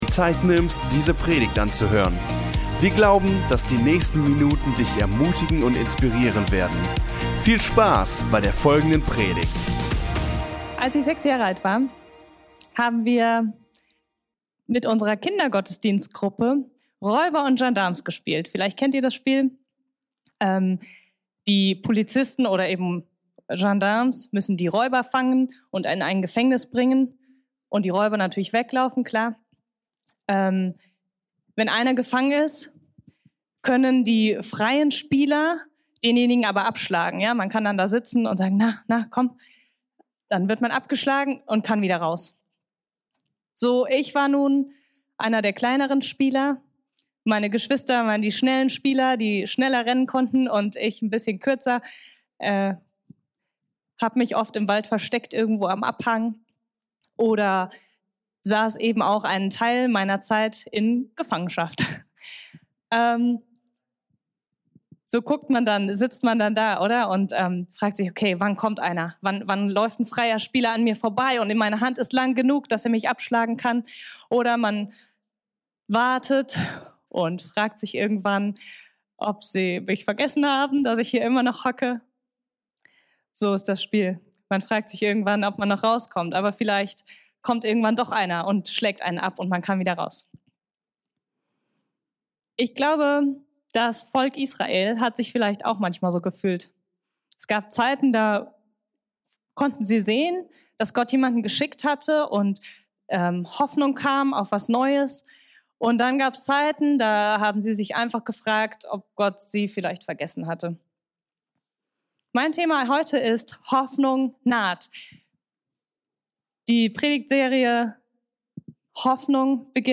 Der erste Teil unserer Predigtserie: "Hoffnung" Folge direkt herunterladen